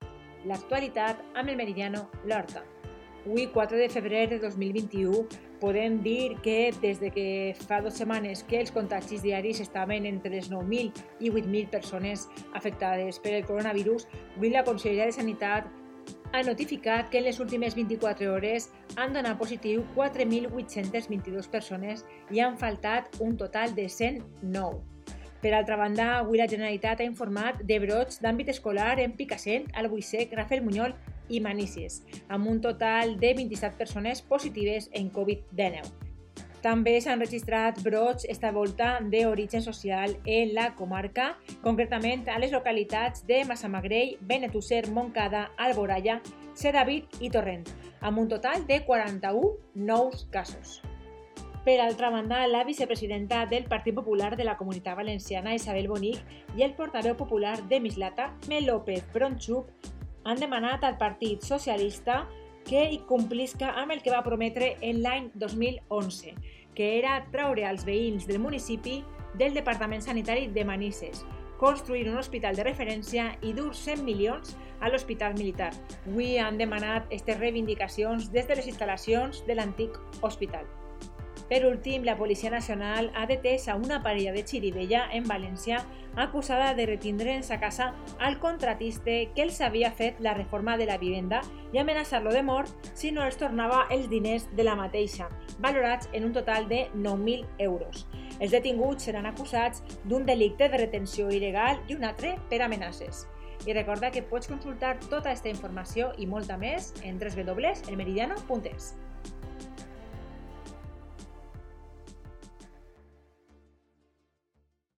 Informativo 4/2/21: